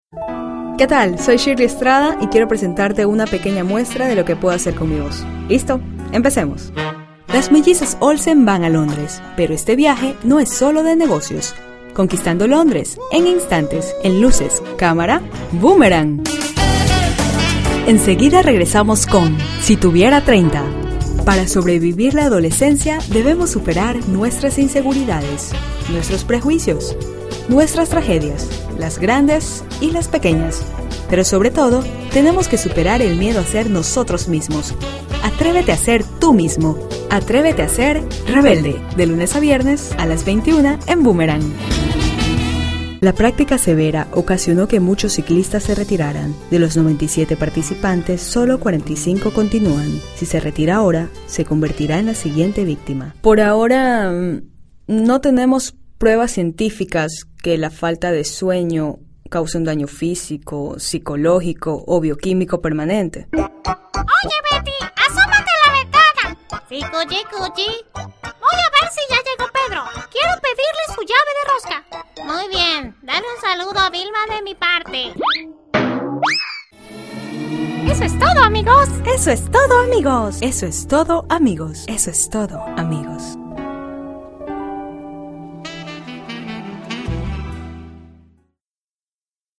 Locuiones de todo tipo: publicidad, doblaje, centrales telefĂłnicas (conmutador), documentales, etc. Acento neutro.
Sprechprobe: Werbung (Muttersprache):